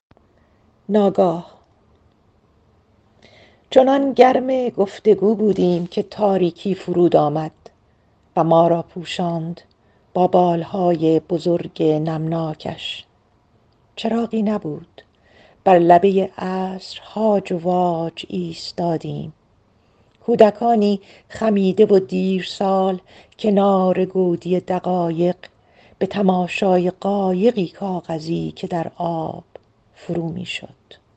A Glimpse read in Persian